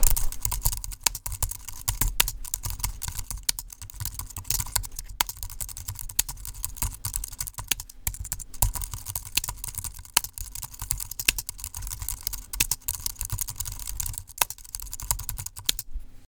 Fast Keyboard Typing
Fast Foley Keyboard Typing sound effect free sound royalty free Memes